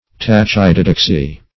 Search Result for " tachydidaxy" : The Collaborative International Dictionary of English v.0.48: Tachydidaxy \Tach"y*di*dax`y\, n. [Gr. tachy`s quick + di`daxis teaching.]